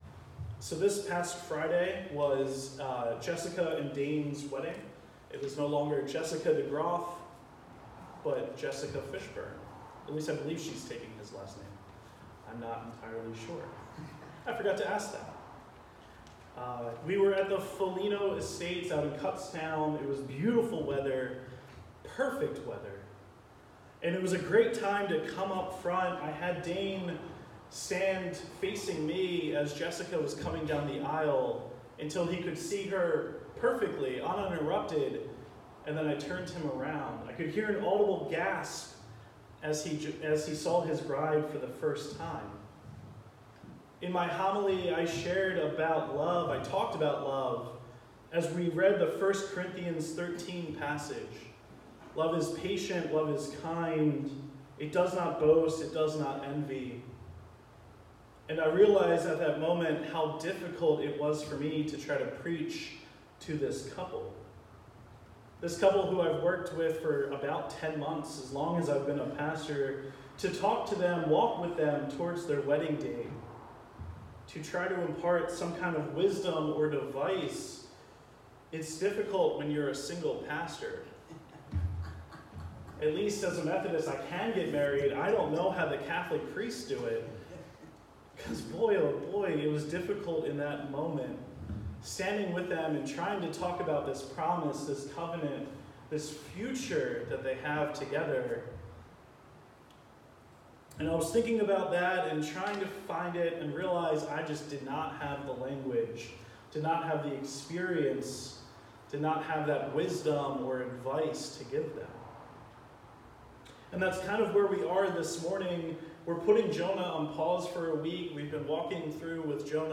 Sermon-6-9-19.mp3